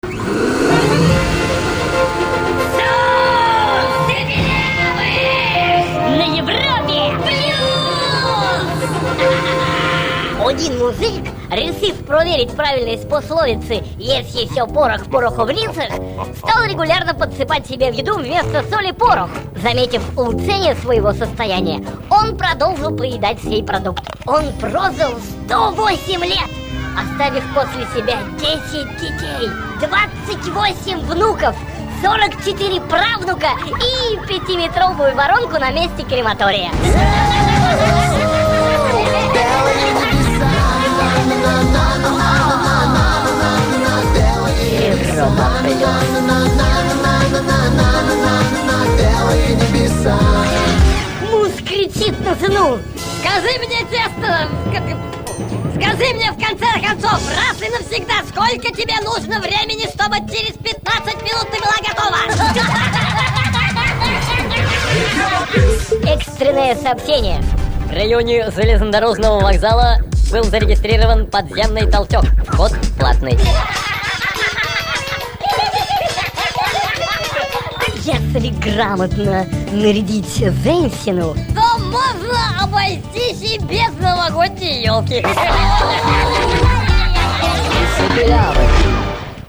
Шоу шипилявых(the best) Европа+ Собраны приколы шипилявых с известого всем радио